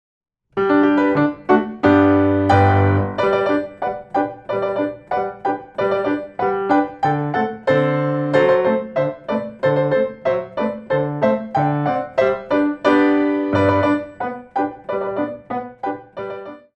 4 bar intro 2/4